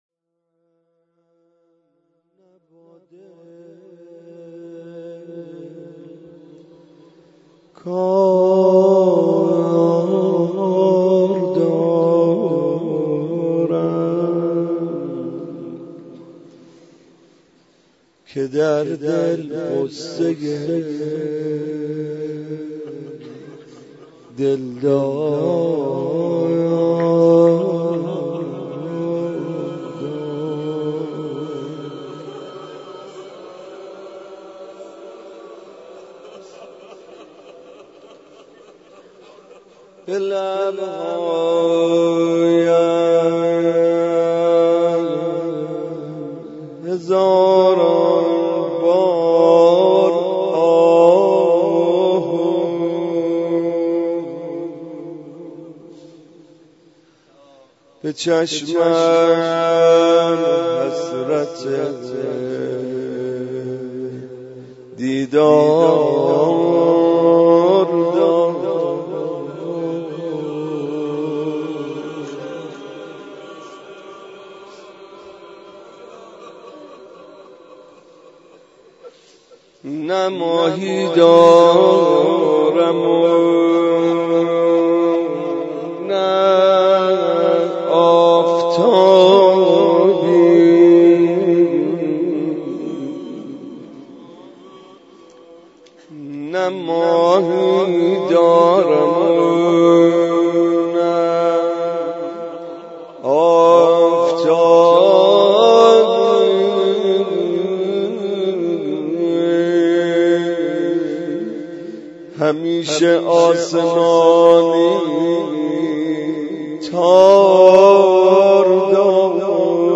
مراسم شب نهم ماه مبارک رمضان
مناجات